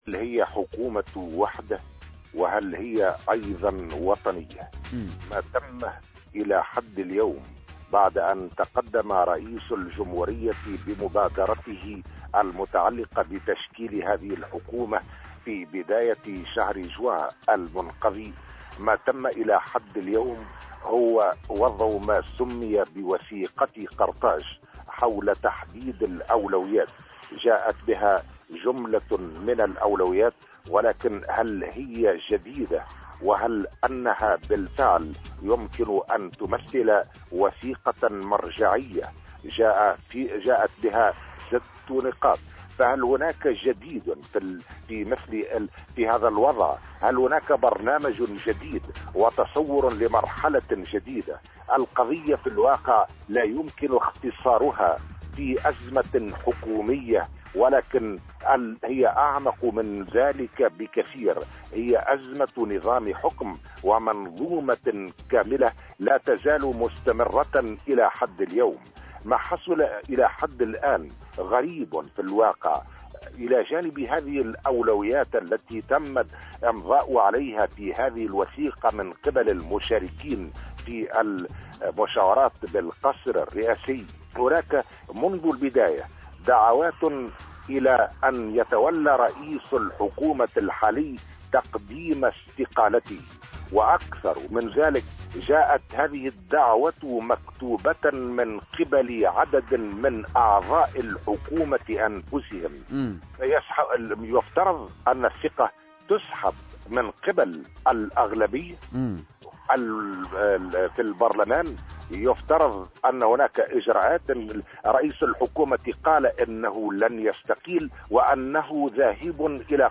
وأضاف في مداخلة له اليوم على "الجوهرة أف أم" أن الحبيب الصيد ليس في وضع مريح من الناحية السياسية كي يطلب تجديد الثقة فيه عملا بأحكام الفصل 98 من الدستور، خاصة وأن الأغلبية في البرلمان تتجه نحو سحب الثقة منه.